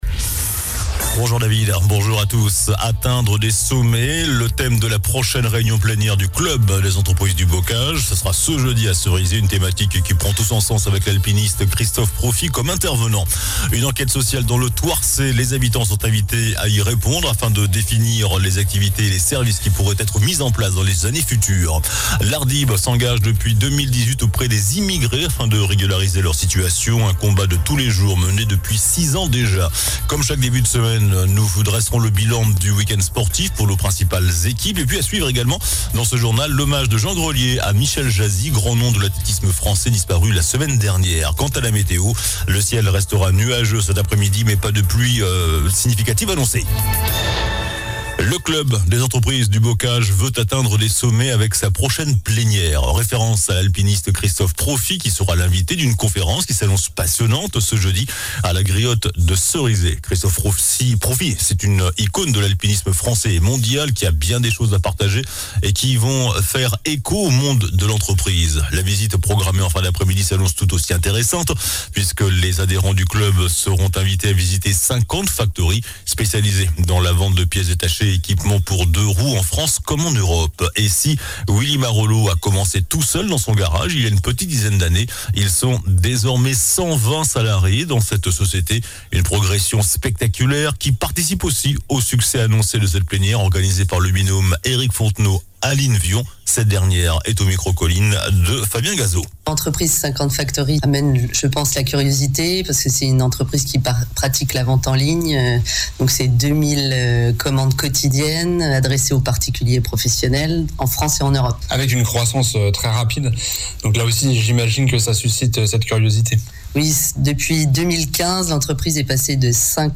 JOURNAL DU LUNDI 05 FEVRIER ( MIDI )